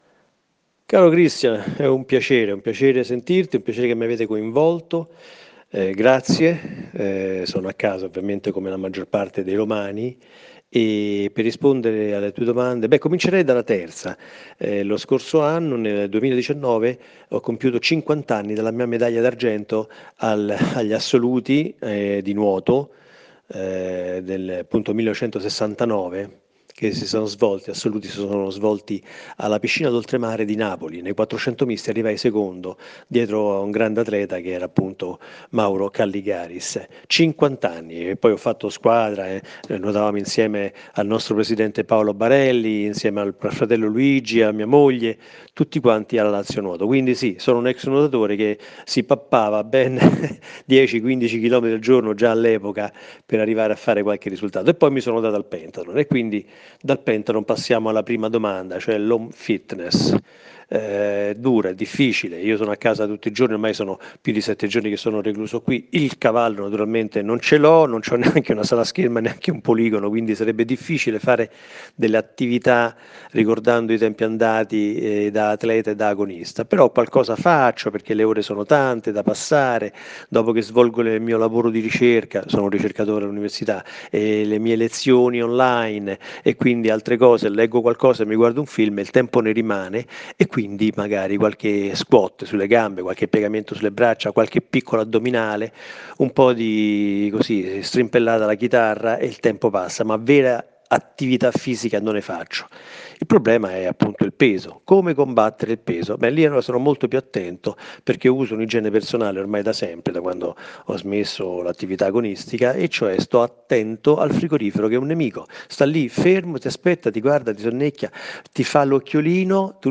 interviste audio